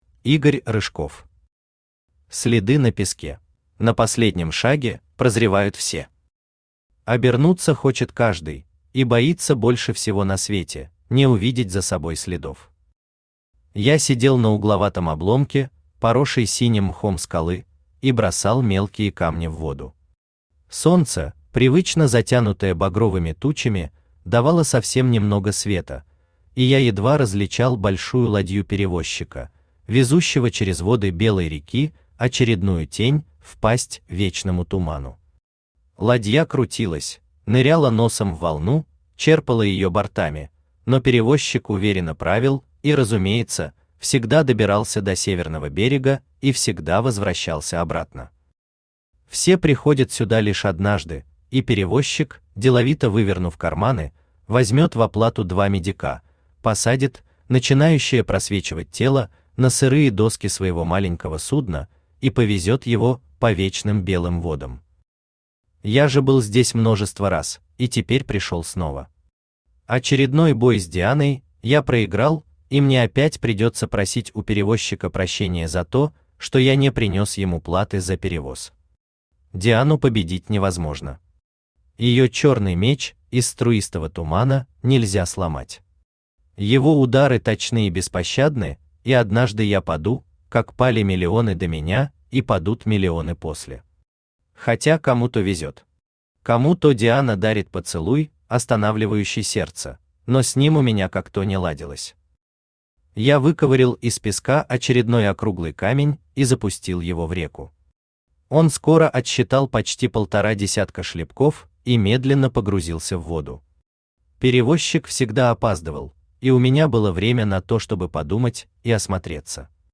Аудиокнига Следы на песке | Библиотека аудиокниг
Aудиокнига Следы на песке Автор Игорь Рыжков Читает аудиокнигу Авточтец ЛитРес.